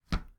step1.wav